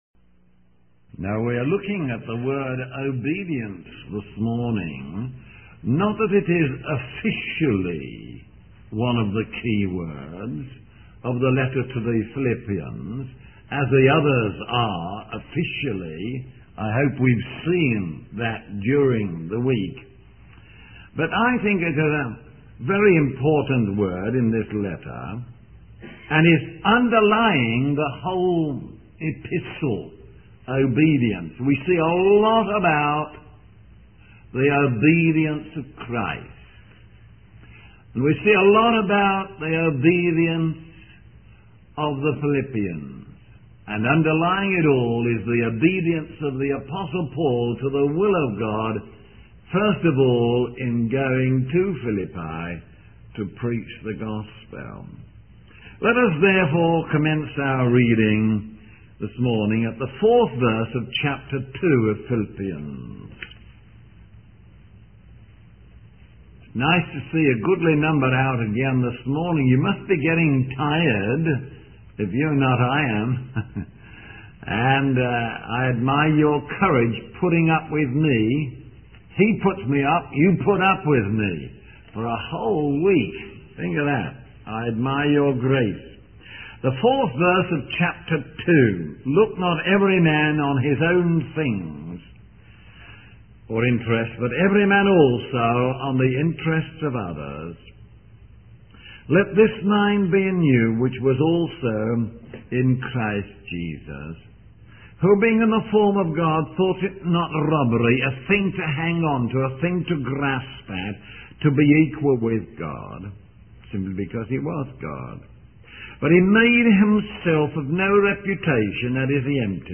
In this sermon, the preacher starts by describing a gathering where the ladies sang and danced, expressing their joy and praise to the Lord.